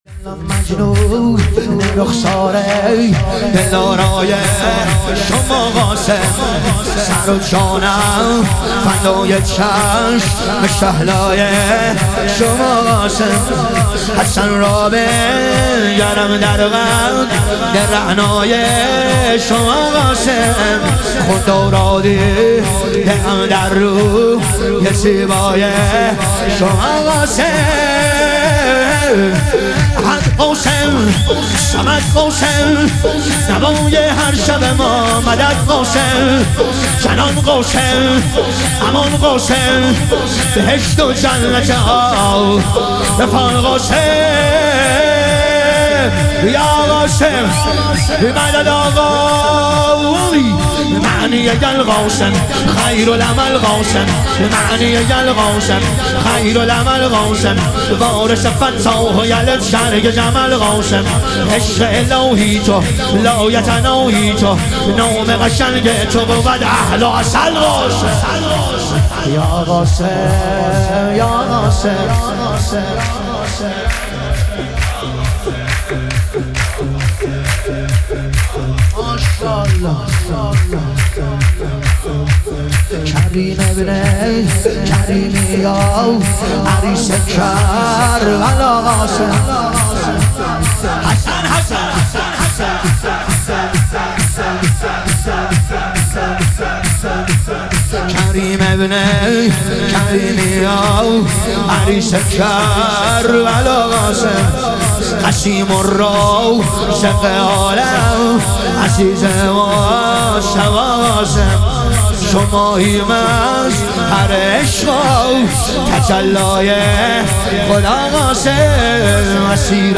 ظهور وجود مقدس حضرت قاسم علیه السلام - شور